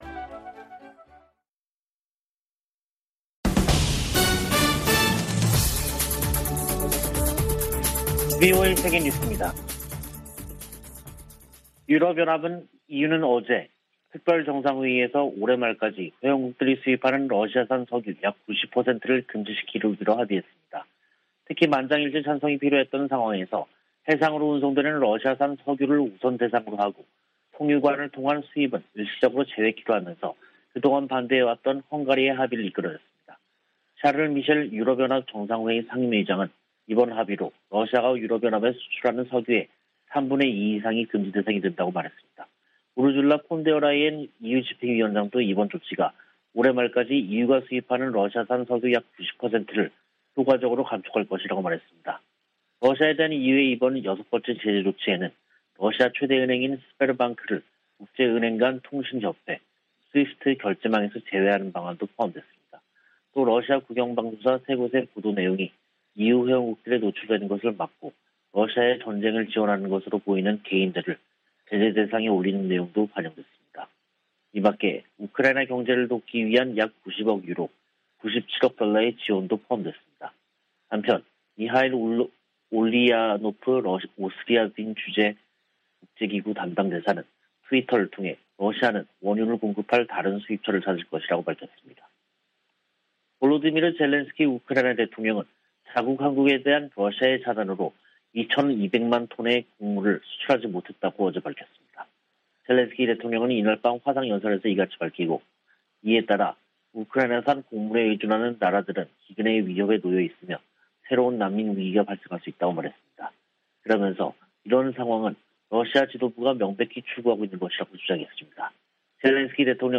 VOA 한국어 간판 뉴스 프로그램 '뉴스 투데이', 2022년 5월 31일 2부 방송입니다. 조 바이든 미국 대통령이 메모리얼데이를 맞아 미군 참전 용사들의 희생을 기리고, 자유민주주의의 소중함을 강조했습니다. 미 연방 상원의원이 메모리얼데이를 맞아 한국전쟁에서 숨진 미 육군 병장의 공로를 기렸습니다. 북한의 대표적 석탄 항구인 송림항에 이달 들어 대형 선박 8척이 드나든 것으로 나타났습니다.